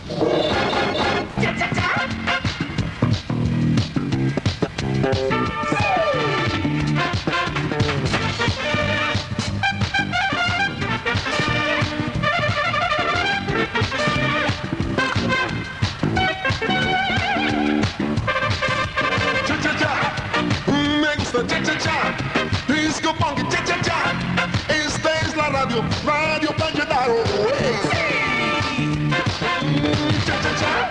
Cançó de la ràdio, a ritme de txa-txa-txa